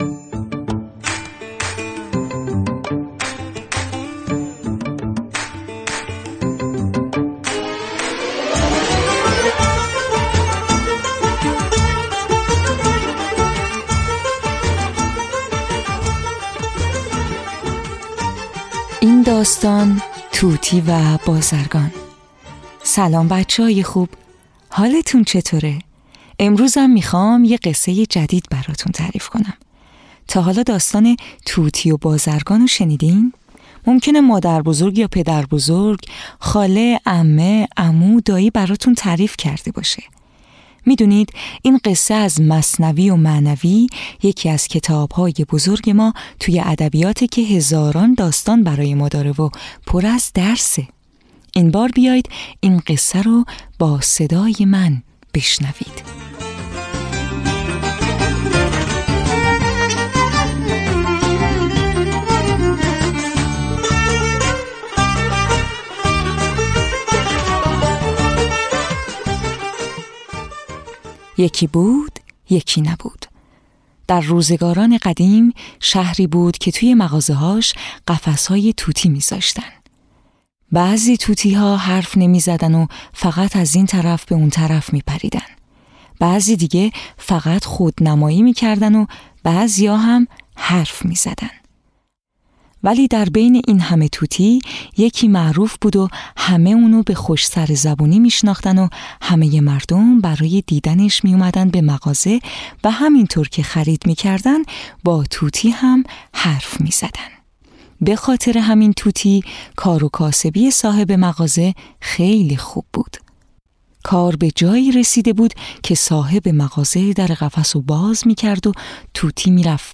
قصه های کودکانه صوتی - این داستان: ربات و روباه
تهیه شده در استودیو نت به نت